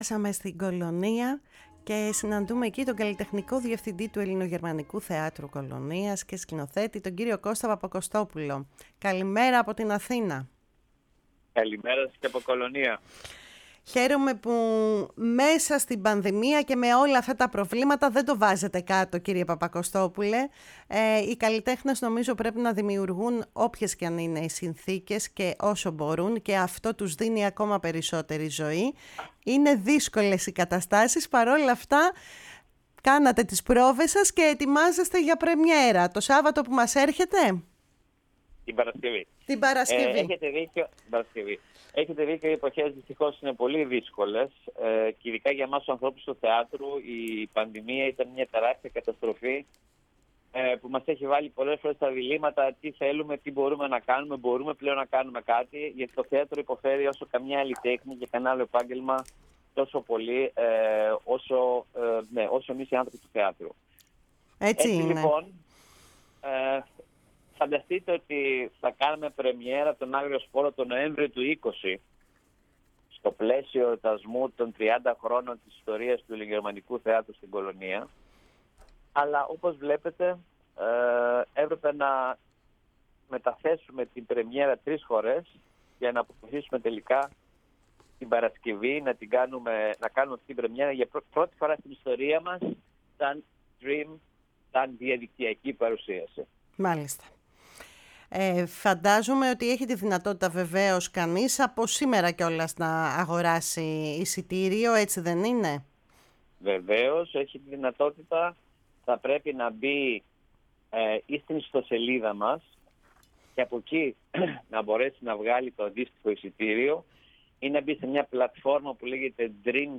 ο οποίος μίλησε στη “Φωνή της Ελλάδας” και συγκεκριμένα στην εκπομπή “Κουβέντες μακρινές”